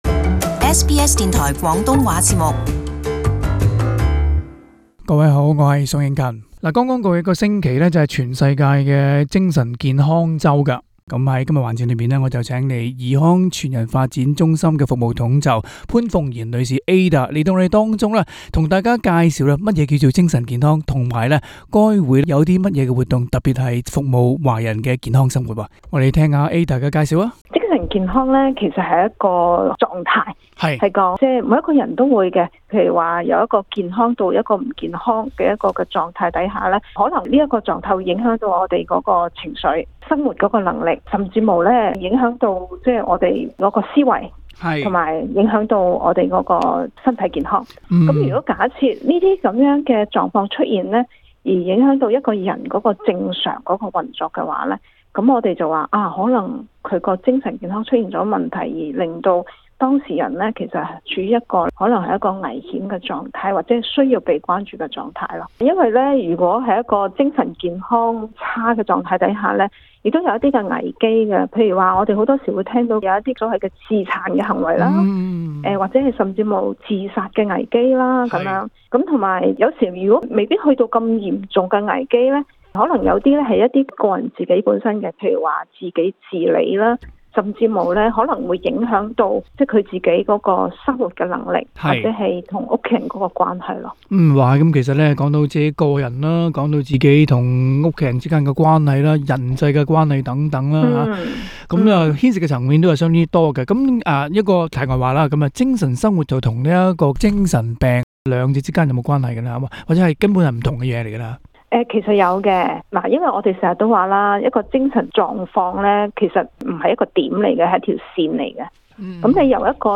SBS Cantonese